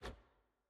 sfx-jfe-ui-generic-rewards-hover.ogg